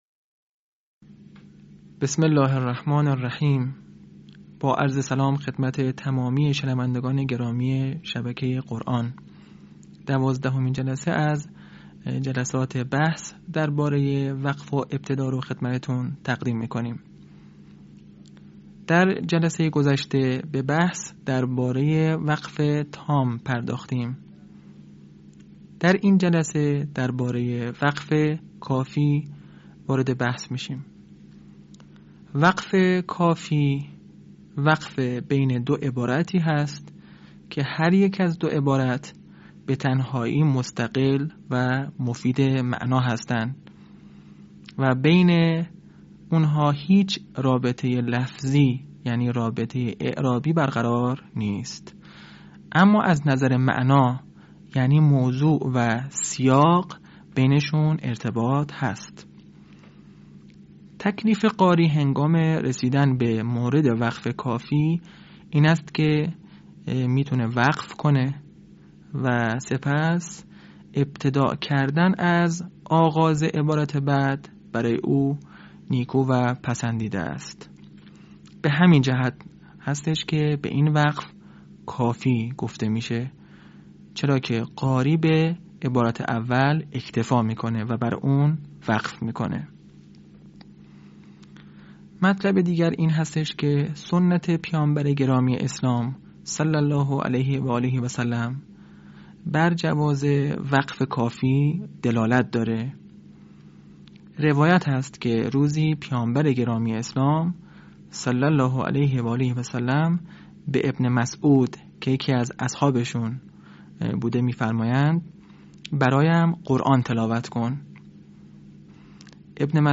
به همین منظور مجموعه آموزشی شنیداری (صوتی) قرآنی را گردآوری و برای علاقه‌مندان بازنشر می‌کند.